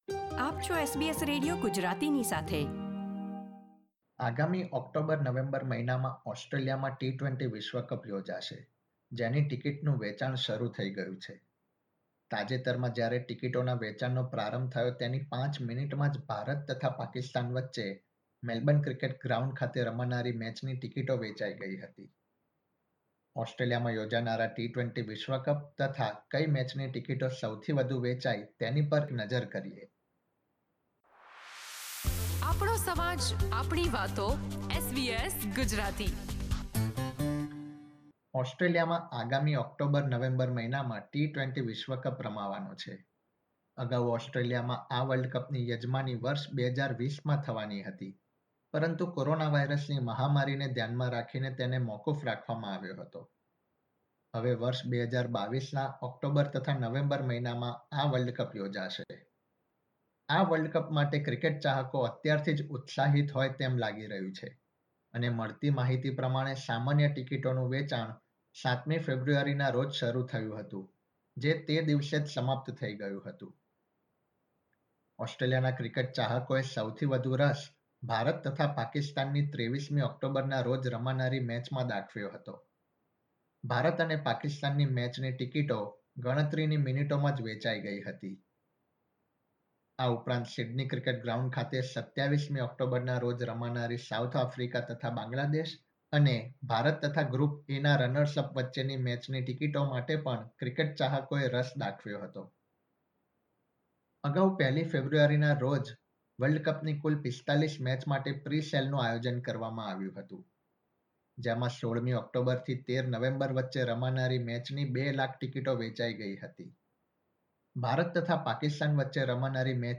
ઓસ્ટ્રેલિયામાં રમાનારા વર્લ્ડ કપ તથા કઇ મેચની ટિકિટો સૌથી વધુ વેચાઇ તે વિશે અહેવાલ.